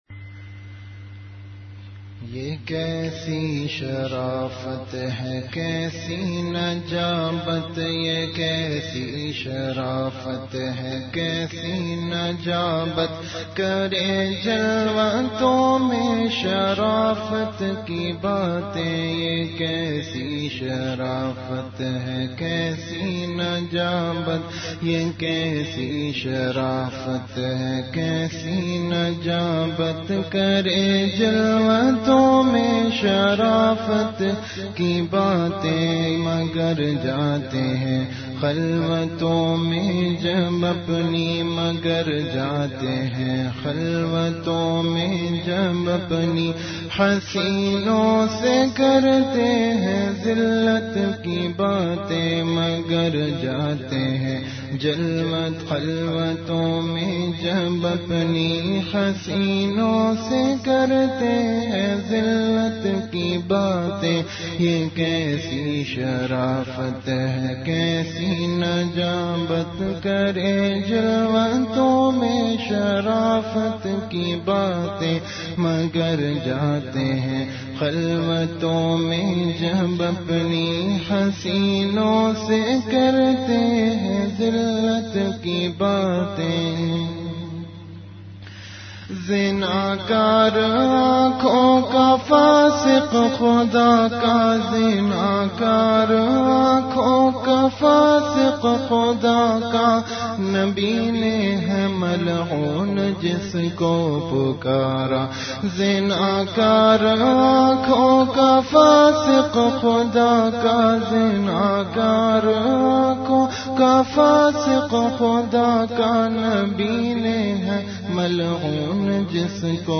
Category Majlis-e-Zikr
Event / Time After Isha Prayer